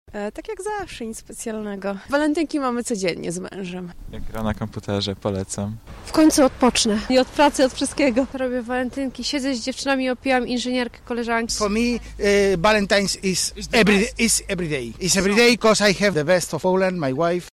Zapytaliśmy mieszkańców miasta i przyjezdnych o ich plany na ten wyjątkowy dzień
sonda